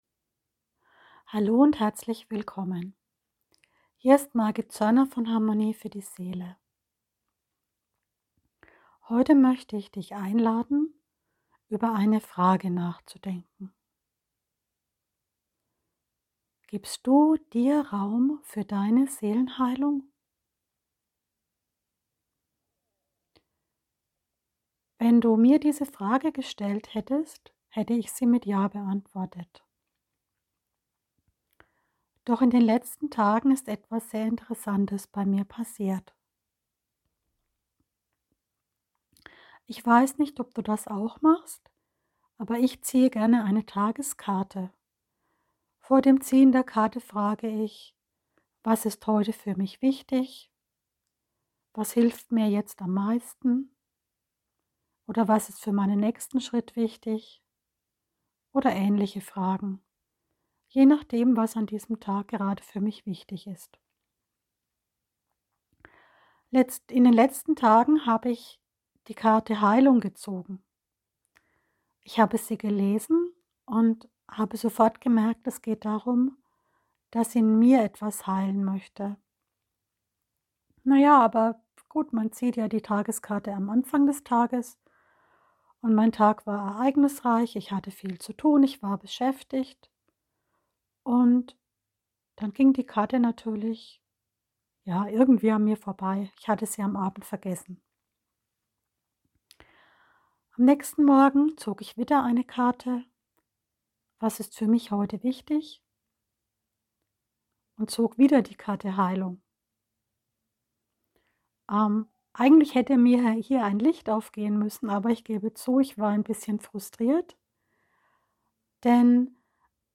Seelenheilung Reflexionsfrage – Live Aufnahme